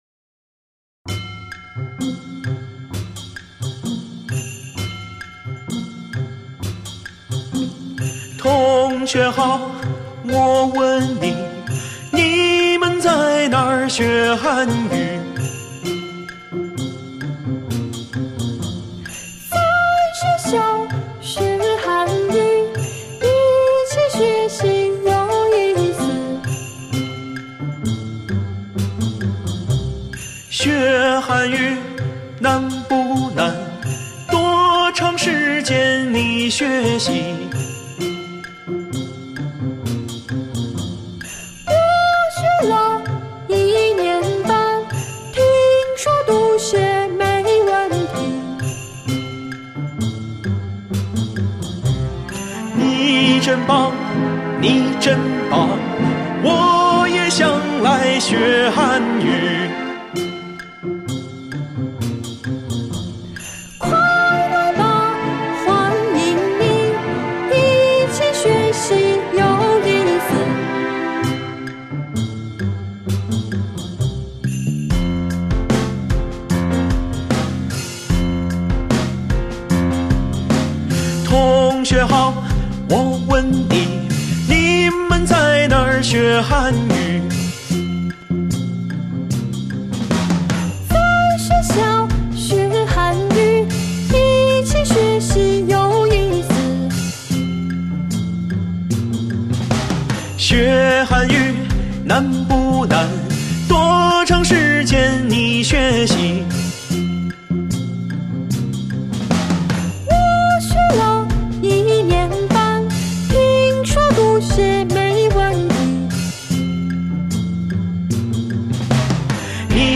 Cùng hát nhé
Bây giờ, chúng ta cùng ôn lại bài thông qua bài hát vui nhộn dưới đây nhé